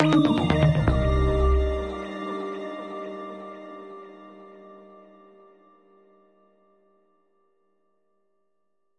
公共广播消息前奏
描述：这里有一个小小的演示，公共广播消息前奏！ 在Mixcraft制造。
标签： 愉快 公共 公告 无线电 新闻 播客 叮铃 钢琴
声道立体声